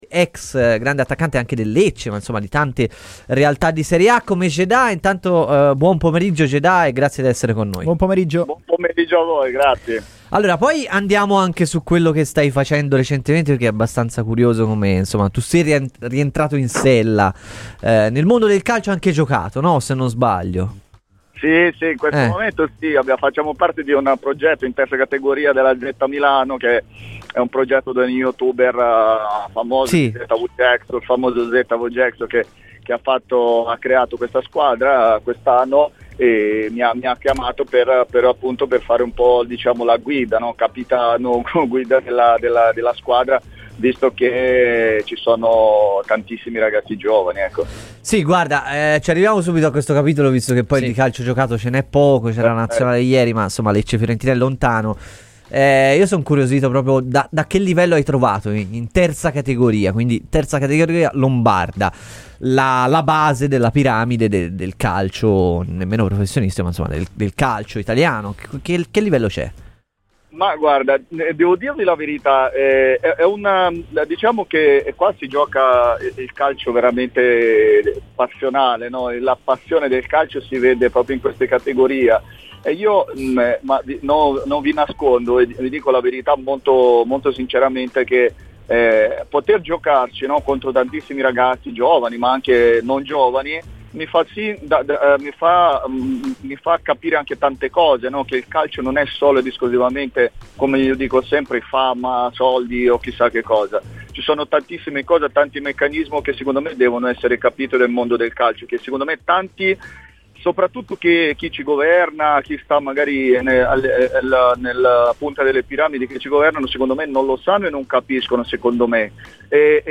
È un allenatore che vuole il gioco, vuole arrivare alla vittoria con una logica" ASCOLTA LA VERSIONE INTEGRALE DEL PODCAST